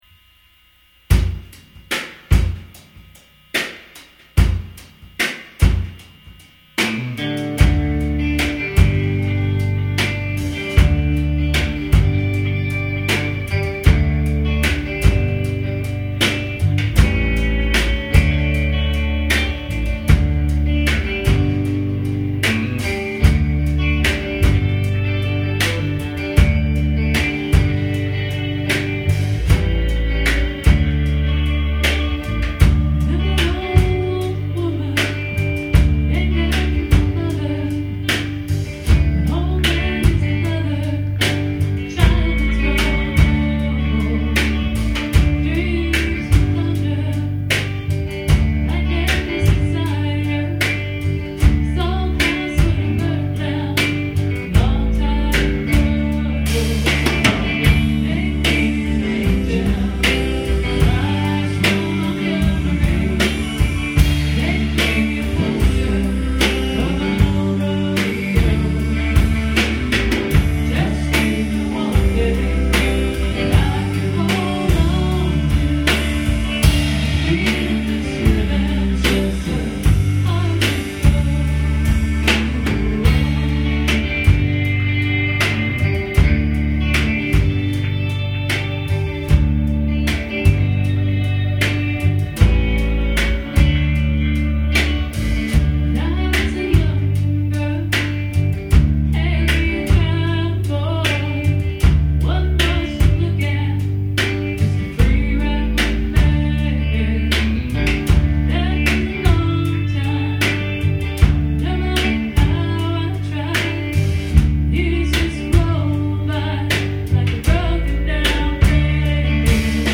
(THESE ARE LIKELY TO BE A LITTLE ROUGH AROUND THE EDGES)